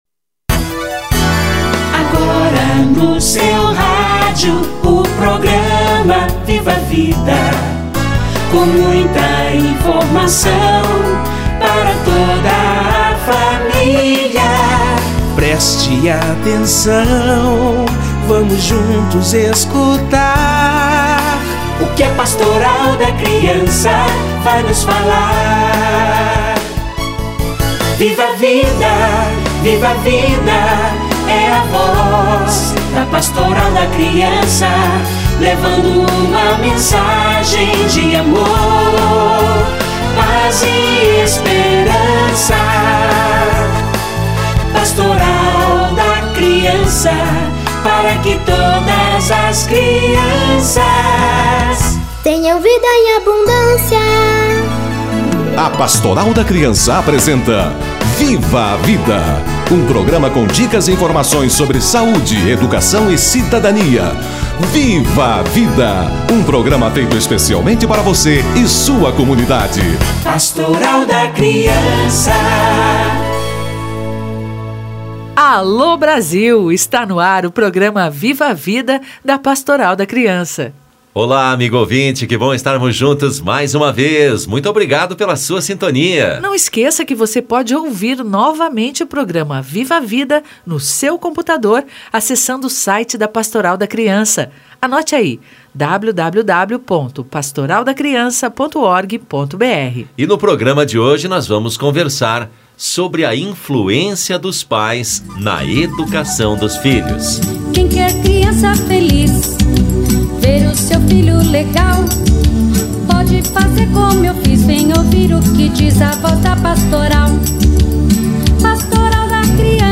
Influência dos pais na educação dos filhos - Entrevista